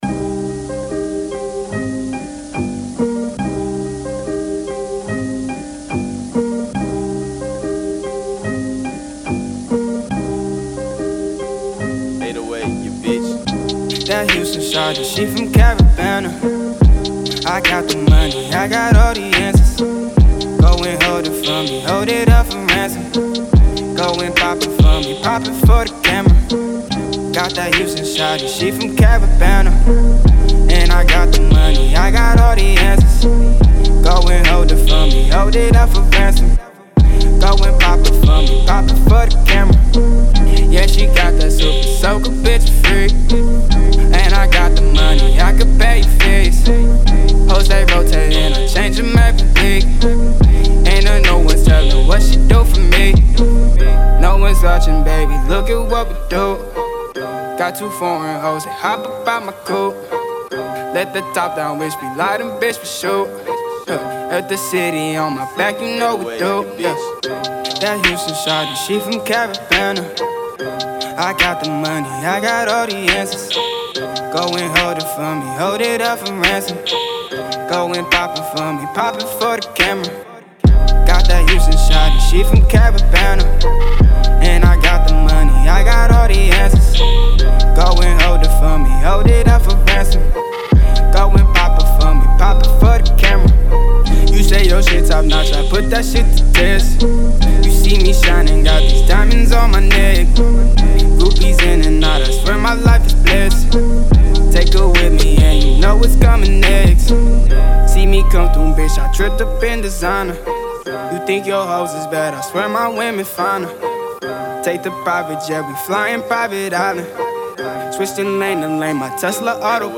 Description : Hip Hop sound